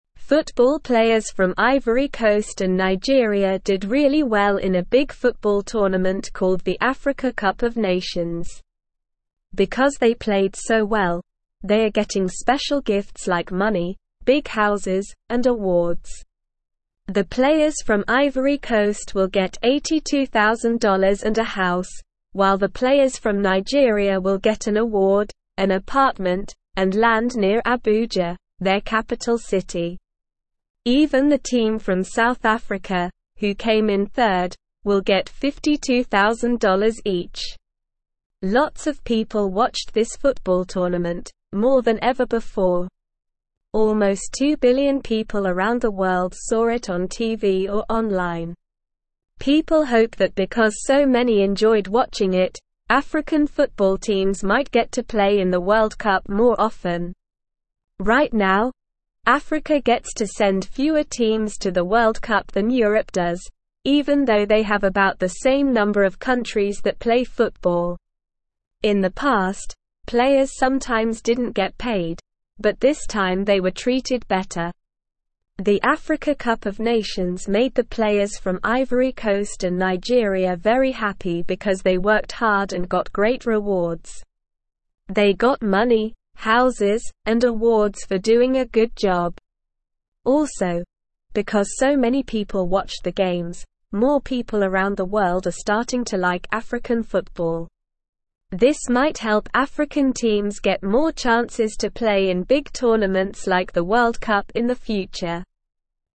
Slow
English-Newsroom-Lower-Intermediate-SLOW-Reading-Special-Gifts-for-African-Football-Players-after-Big-Game.mp3